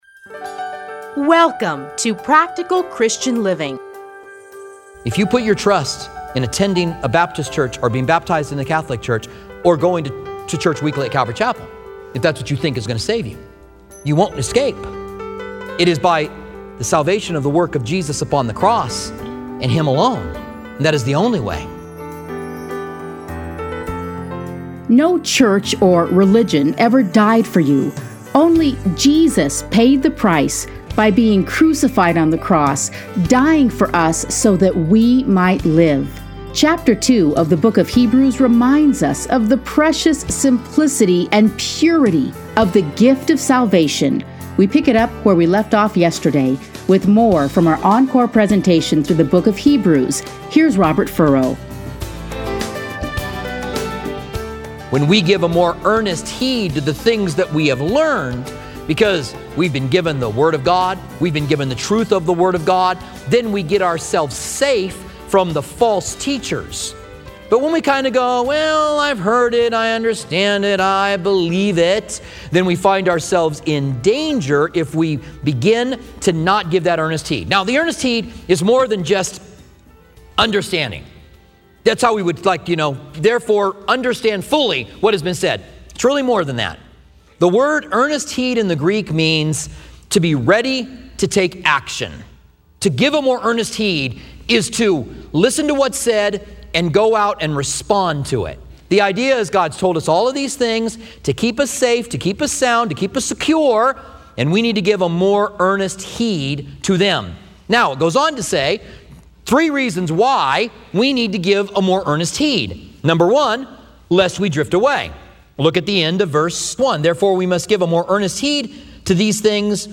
Listen here to a teaching from Hebrews.